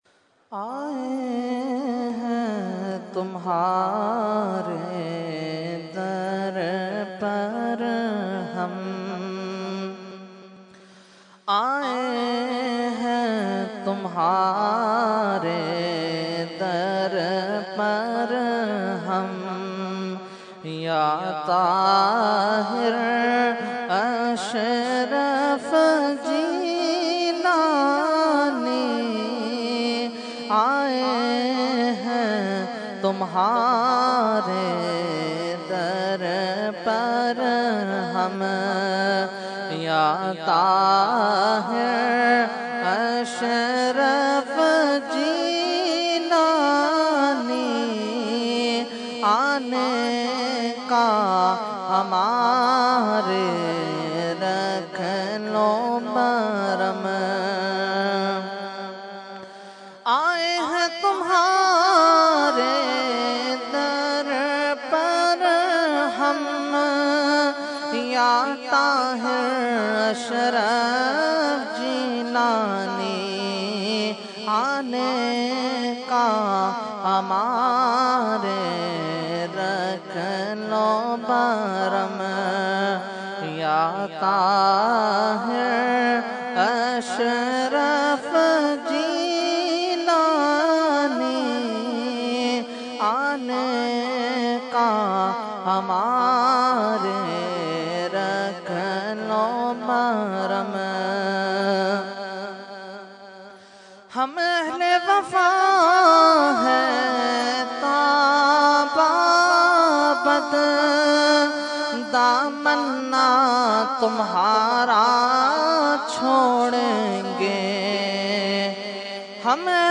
Category : Manqabat | Language : UrduEvent : Urs e Makhdoom e Samnani 2015